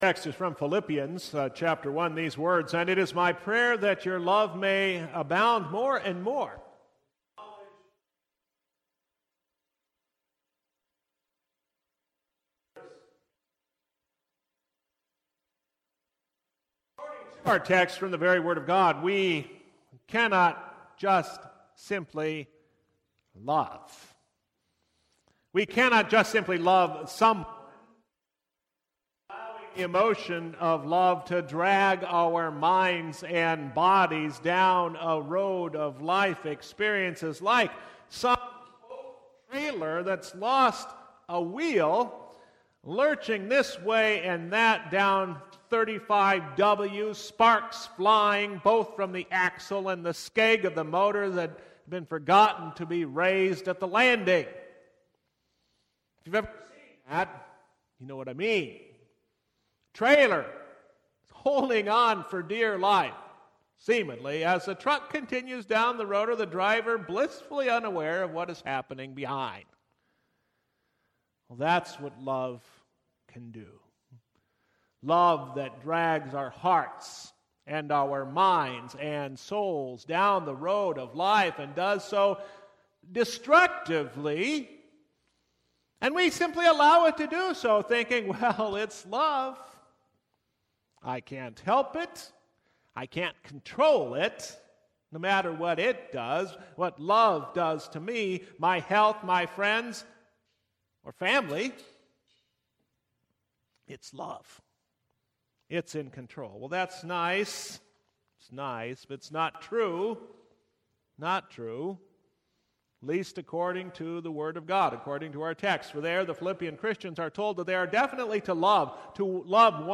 Service Type: Divine Service I